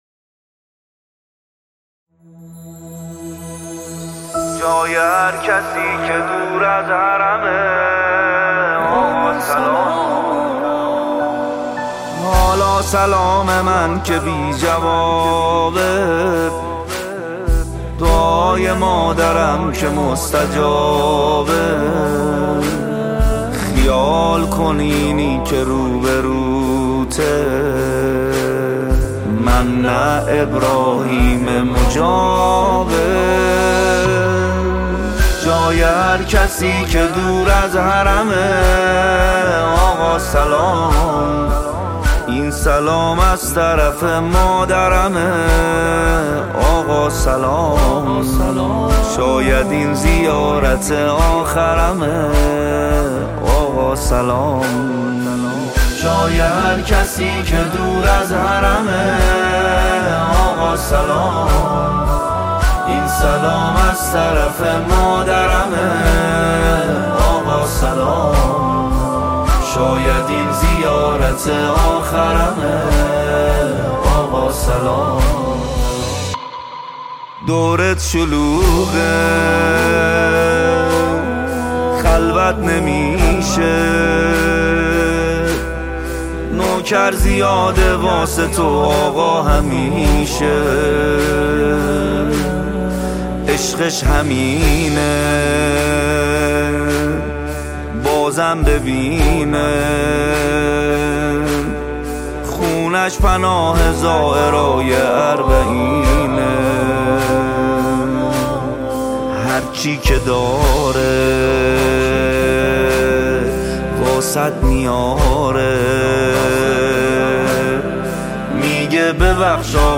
مداحی های منتخب
شب عاشورا